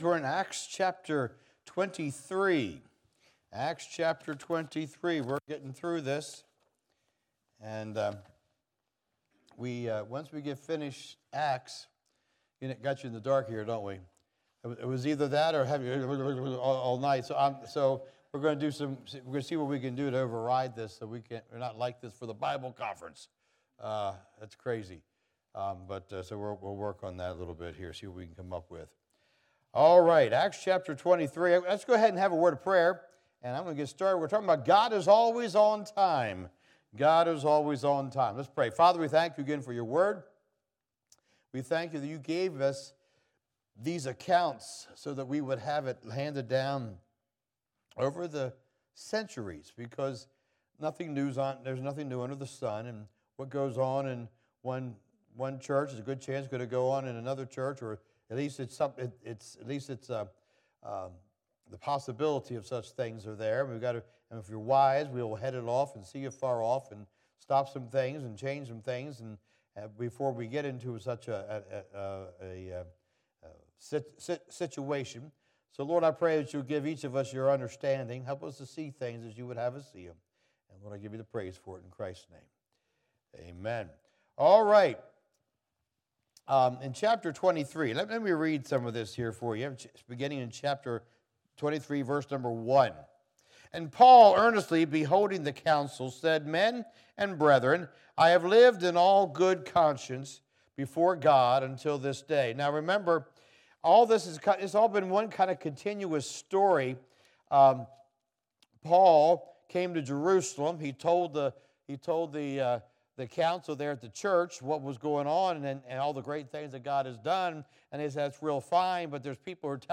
Acts 23:1 Service Type: Wednesday Evening « Thou Sayest